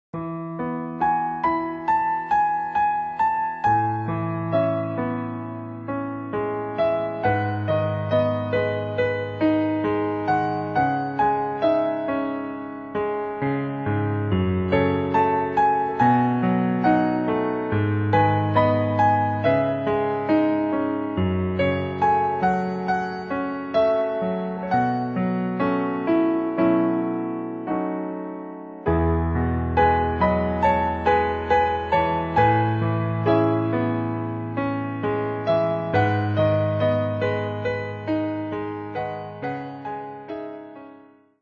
〜 優しさ溢れる4thピアノソロアルバム。